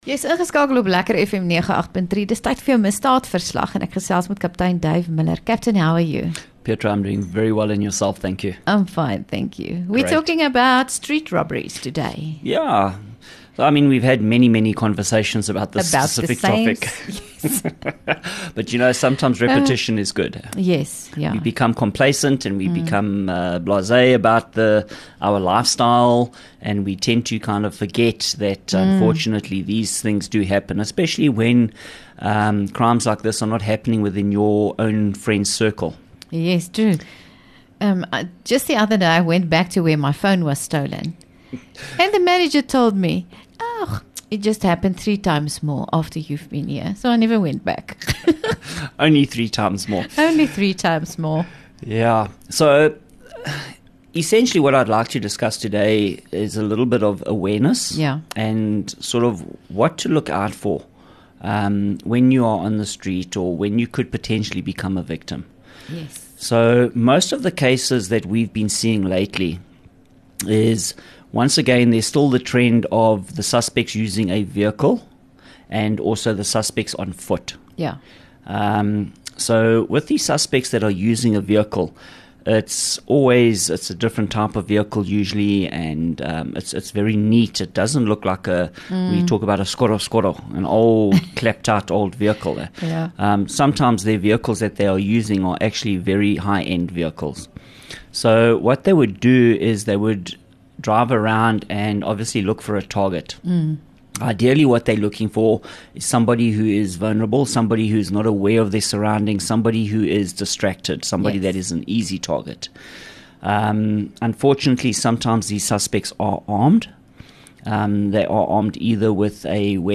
LEKKER FM | Onderhoude 19 Mar Misdaadverslag